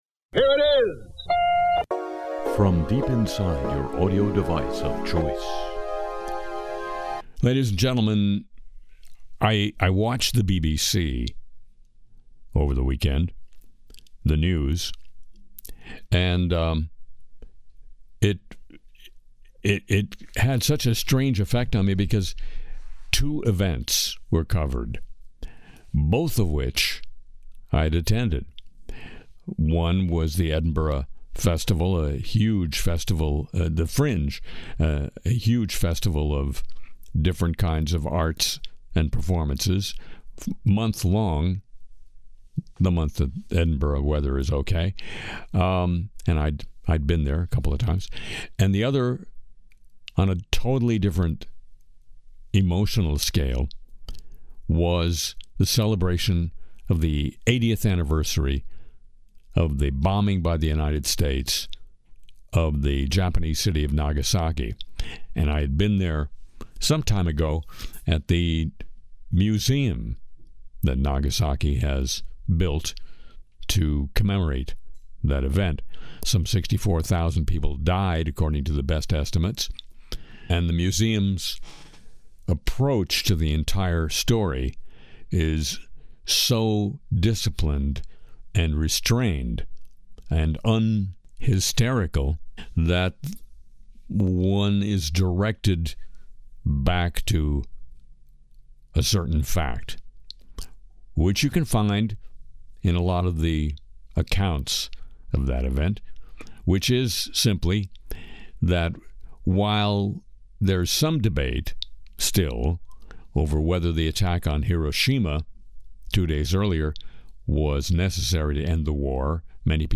Trump Truth Social audio spoof, WNBA crypto sex toy prank, AI misfires, Vegas Sphere Oz, Gaza song & more on Le Show.